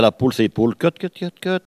Mémoires et Patrimoines vivants - RaddO est une base de données d'archives iconographiques et sonores.
Elle crie pour appeler les poules